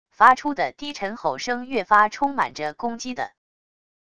发出的低沉吼声越发充满着攻击的wav音频